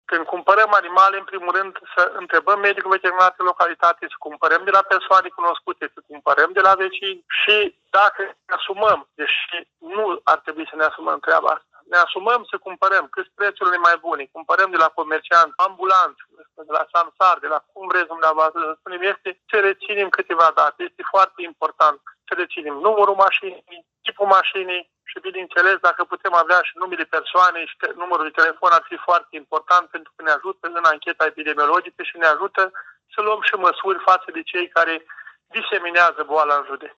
Cazul atrage atenția asupra pericolelor asociate cu achiziționarea de porci din surse necontrolate. Directorul DSVSA Suceava, Mihai Voloșeniuc, atrage atenția că respectarea recomandărilor este foarte importantă pentru prevenirea răspândirii virusului și gestionarea focarelor de pestă.